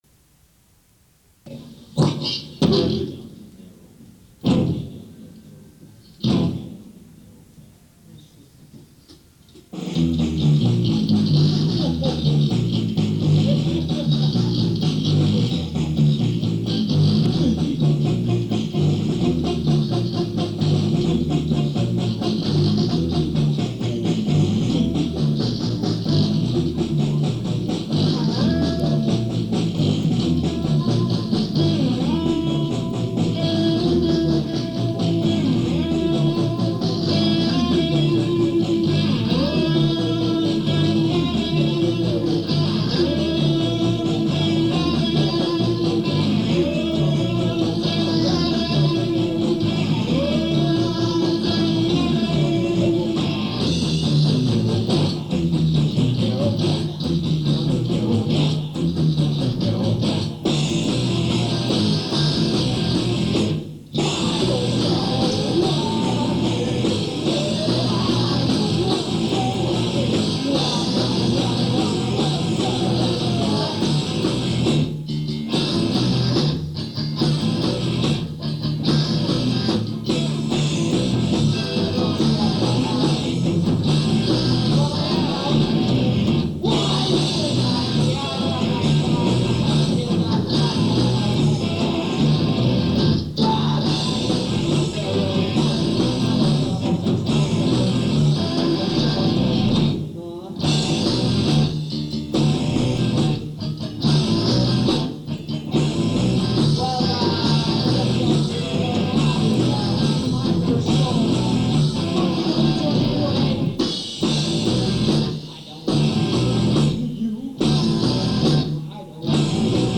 Mothermayi - Practice Recording - The Rapid City Punk Rock Archive